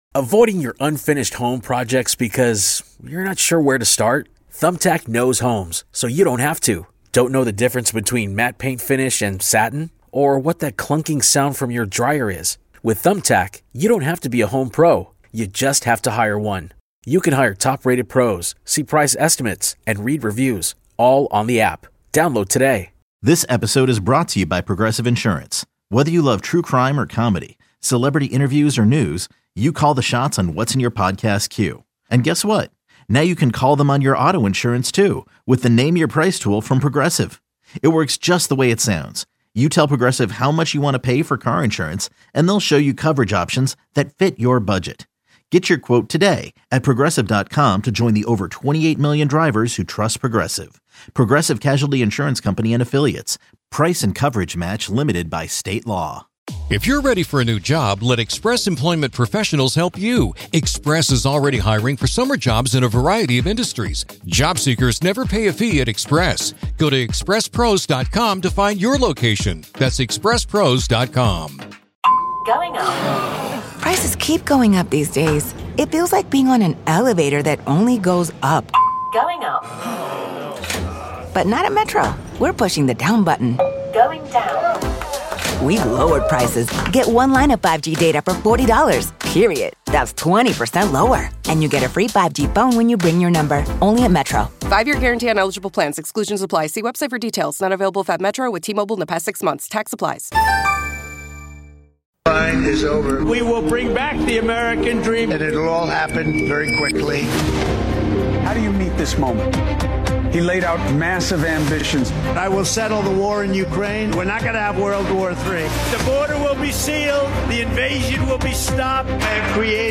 President Trump joins NewsNation for town hall to mark first 100 days of his second term
President Donald Trump joined NewsNation's Chris Cuomo and commentators Bill O'Reilly and Stephen A. Smith for a town hall discussion to mark the first 100 days of his second term.